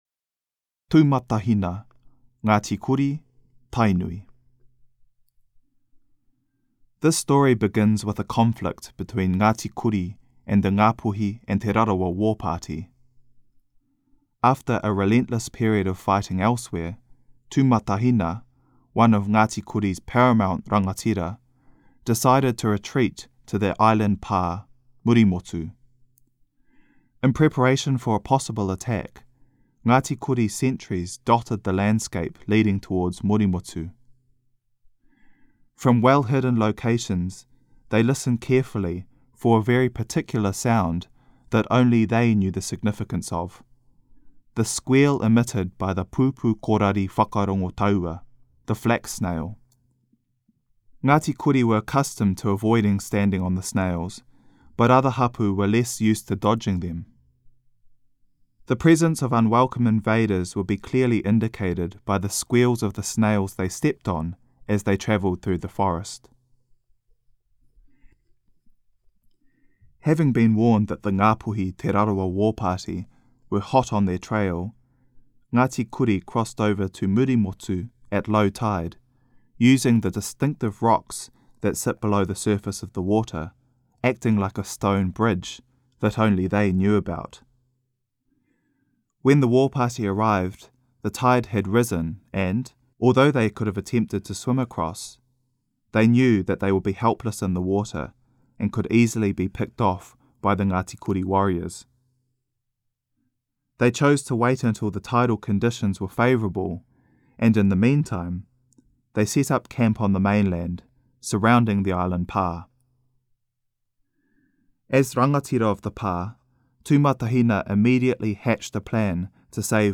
Format: Audiobook
Now published as an audiobook read by the authors themselves, A Fire in the Belly of Hineāmaru is a call to action for Te Tai Tokerau today – a reminder to celebrate the unbroken connection to histories, lands, and esteemed ancestors.